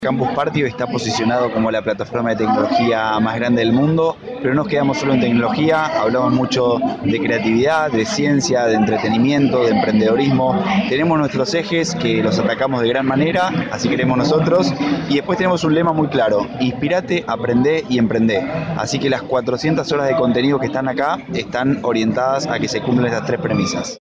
Ciclo 2018, Entrevistas
SobreCiencia está presente en Campus Party 2018 que se realiza hasta el 28 de abril en Tecnópolis, Argentina